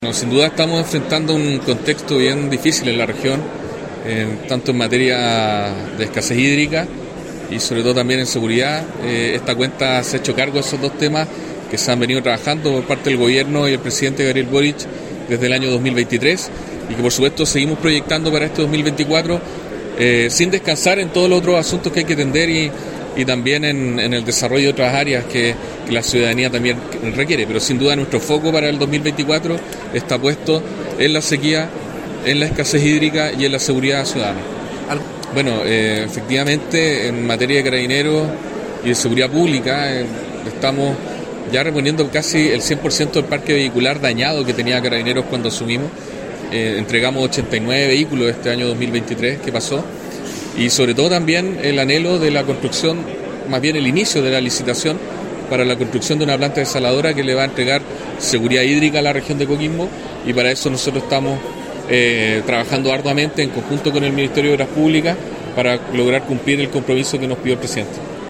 Ante cerca de 200 personas, en el Centro Cultural Palace de Coquimbo, el Delegado Presidencial Regional, Galo Luna, expuso los diversos avances del Gobierno del Presidente Gabriel Boric en la zona.